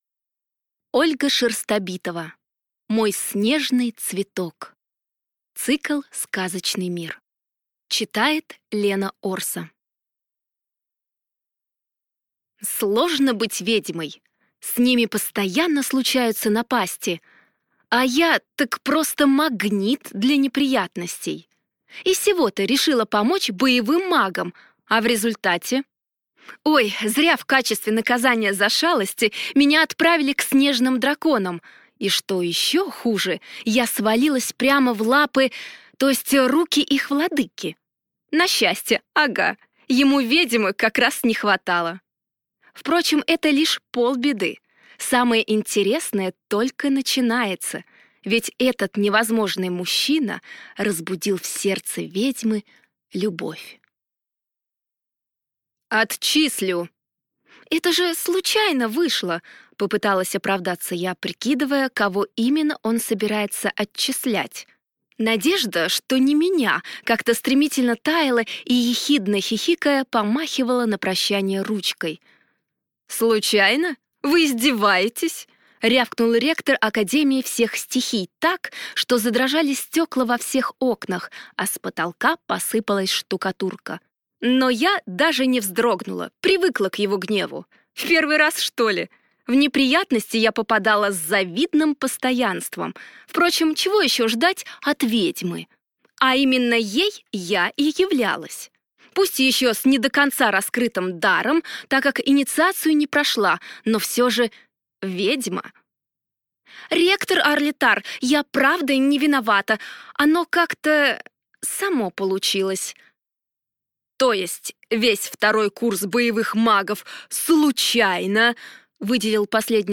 Аудиокнига Мой снежный цветок | Библиотека аудиокниг